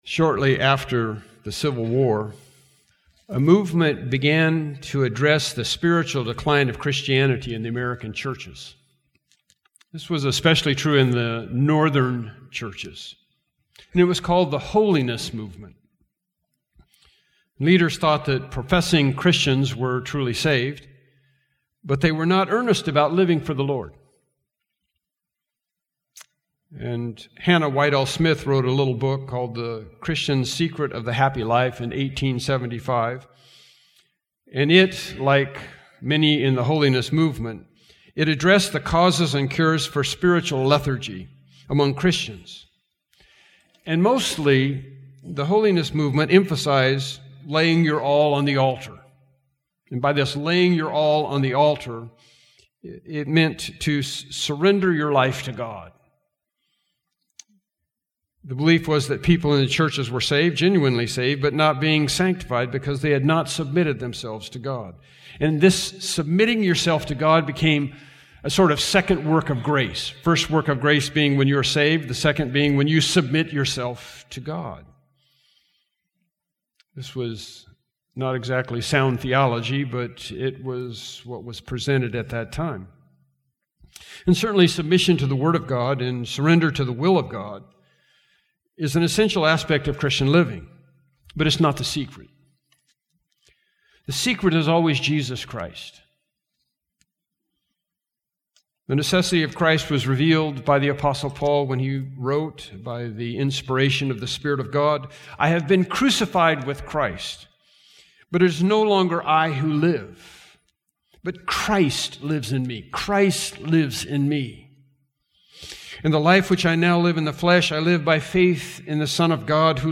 Bible Text: John 12:15-17 | Preacher